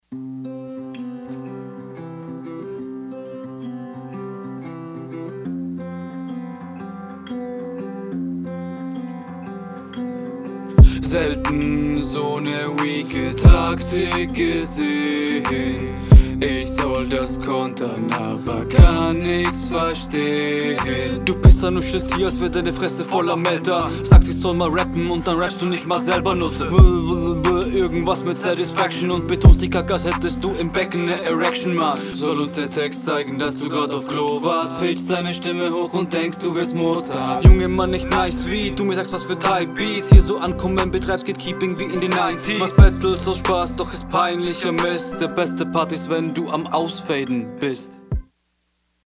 Wirkt nicht ganz so sicher auf dem Beat, die Shuffles sind nicht immer so ganz …
Flow/Gesang : klingt sehr harmonisch aber bei den etwas schneller geflowten Passagen wirst du ein …